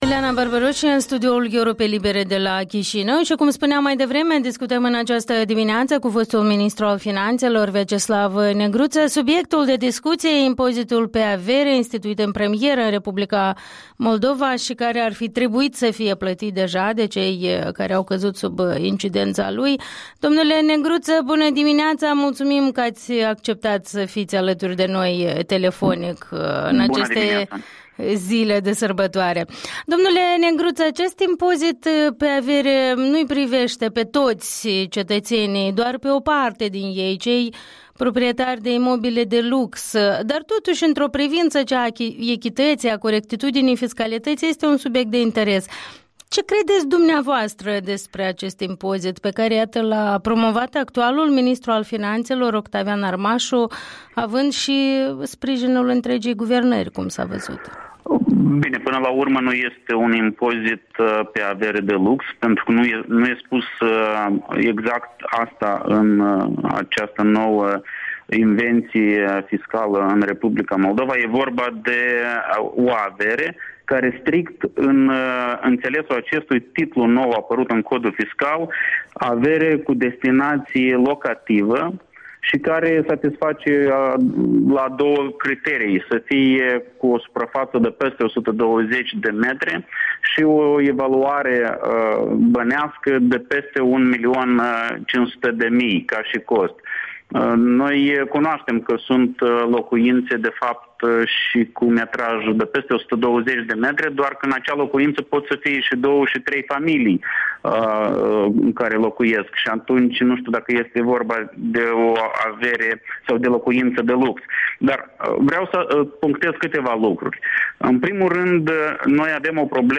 Interviul dimineții: despre impozitul pe avere cu un fost ministru de finanțe.